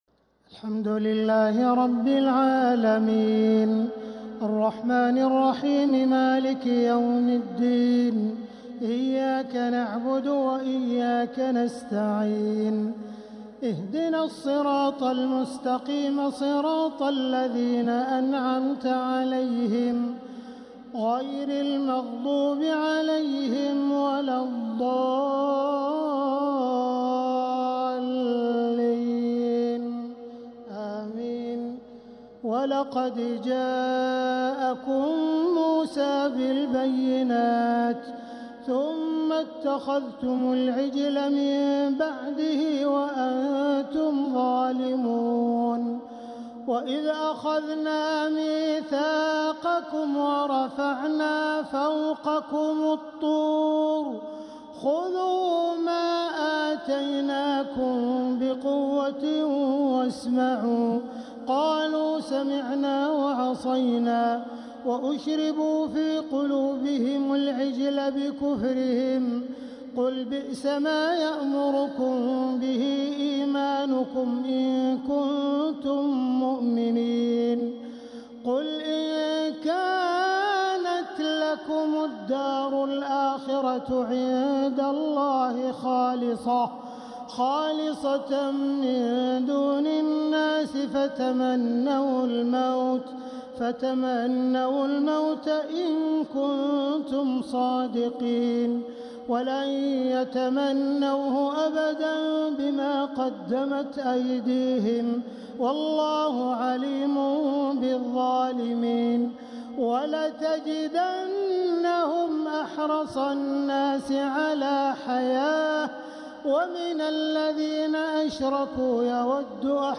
تلاوات التراويح و التهجد كاملة للشيخ عبدالرحمن السديس لعام 1446هـ > تراويح الحرم المكي عام 1446 🕋 > التراويح - تلاوات الحرمين